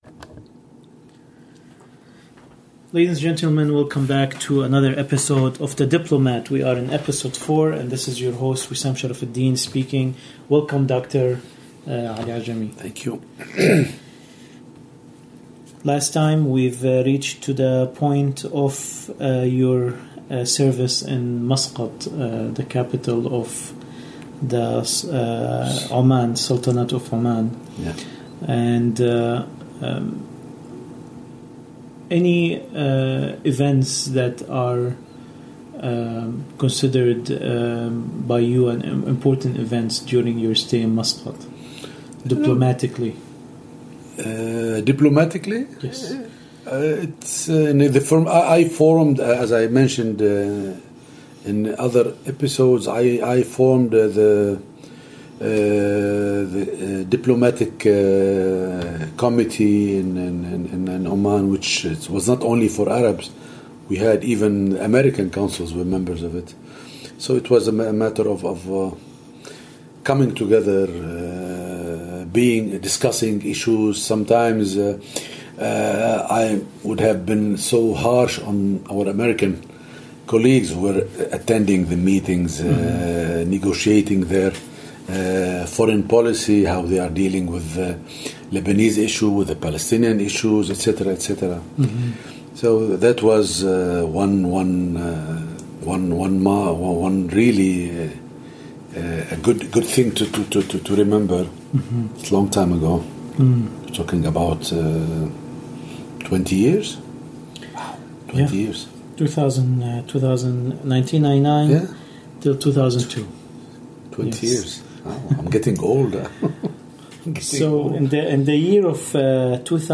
In a series of interviews, Ambassador Dr. Ali Ajami will be reflecting on his life journey as a teacher, a journalist, and a diplomat.